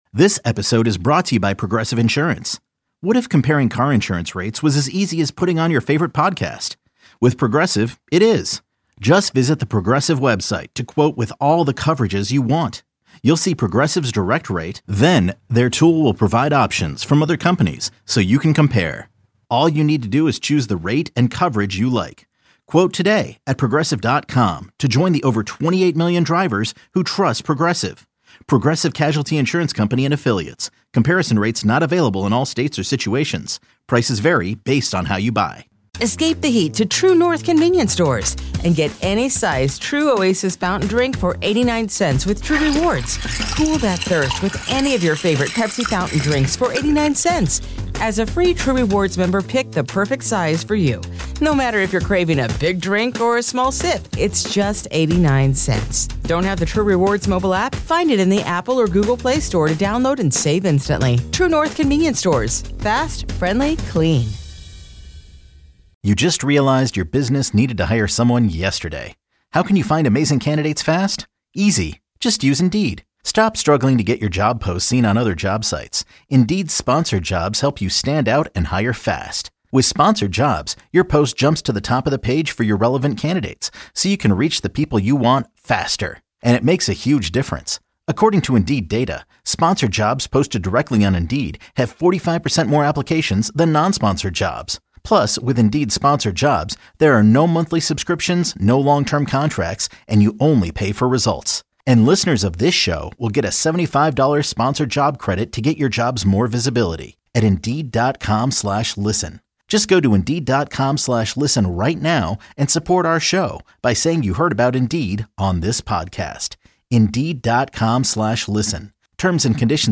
Each 30-minute, feel-good episode introduces listeners to those people--both celebs and regular people alike--shining the spotlight on the good deeds they’re doing.